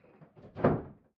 DoorPrigluw.ogg